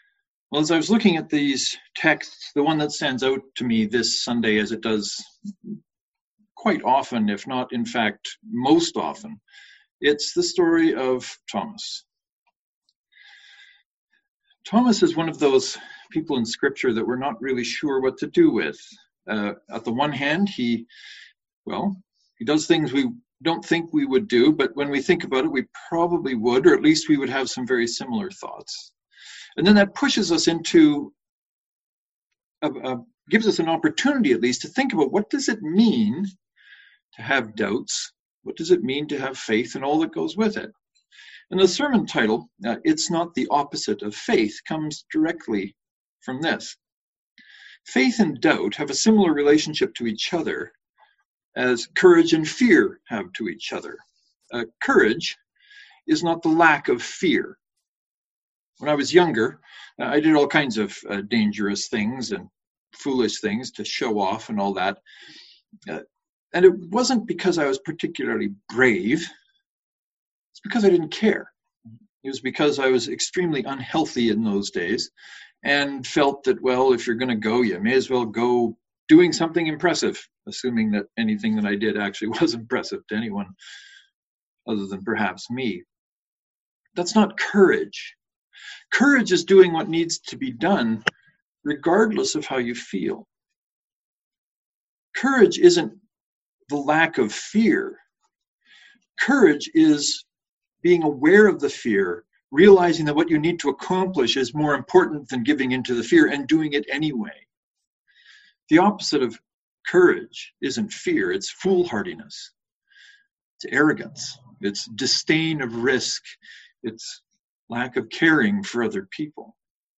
The sermon for this Sunday helps us understand that doubt is not the opposite of faith* but is actually part of being faithful, or faith-filled if you will.